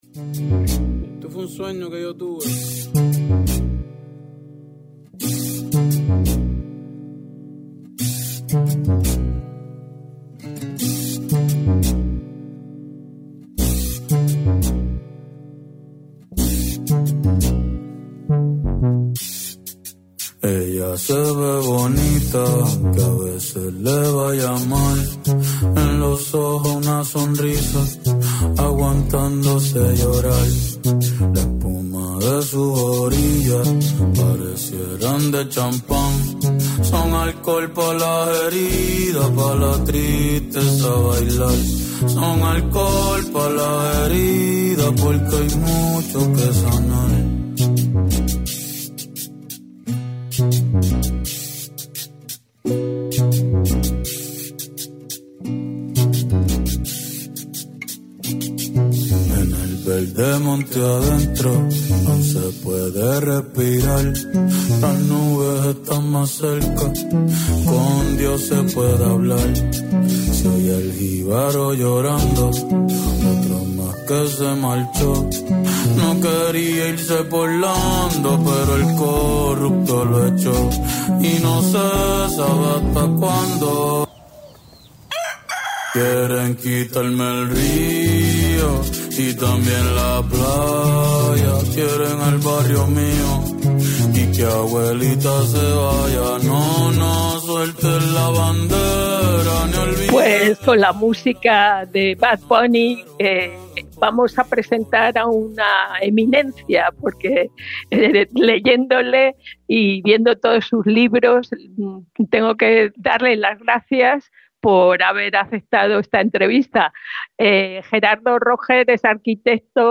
Entrevista a una de las eminencias de España en temas de vivienda